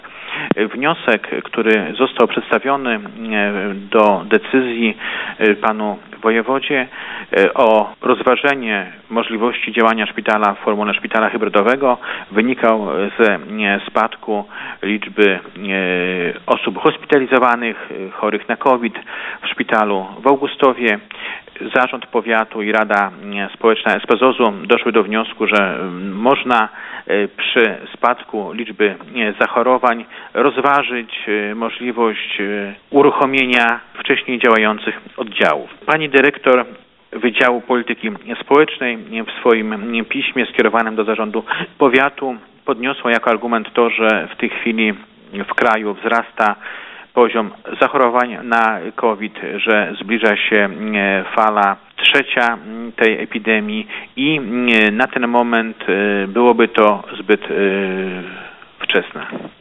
O szczegółach Jarosław Szlaszyński, starosta augustowski.